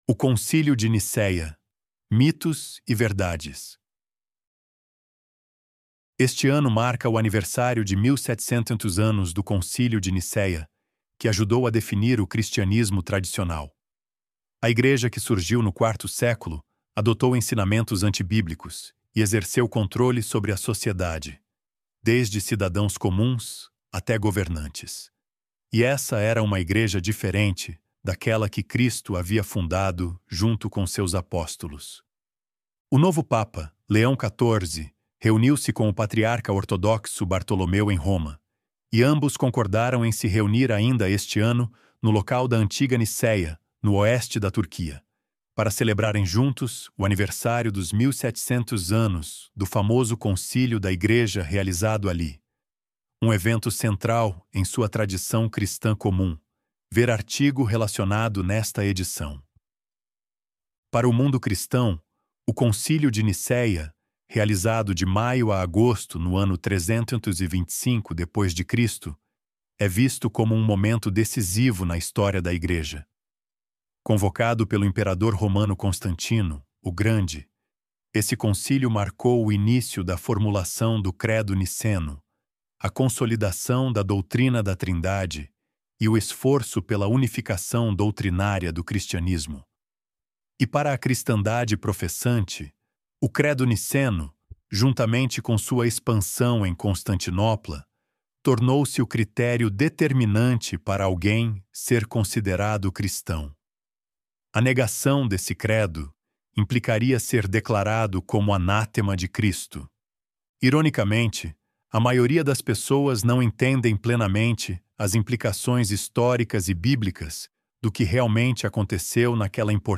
ElevenLabs_O_Concílio_de_Nicéia_Mitos_e_Verdades.mp3